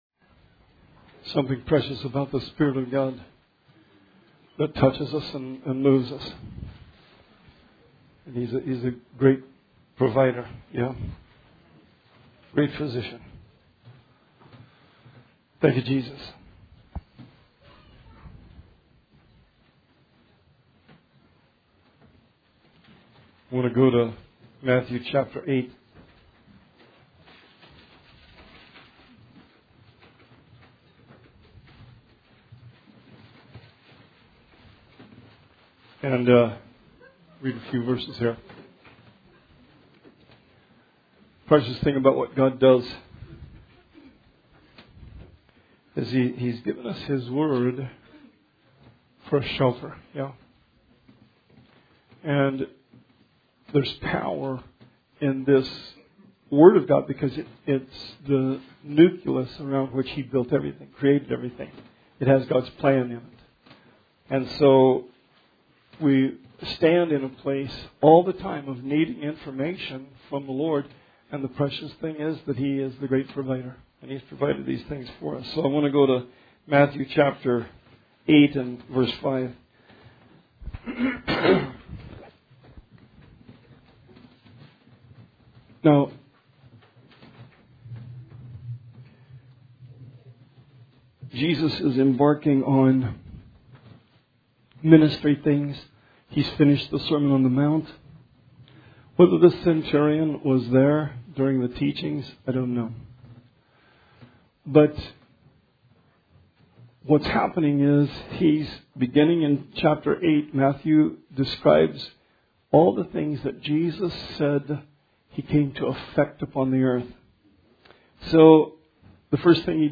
Sermon 11/10/19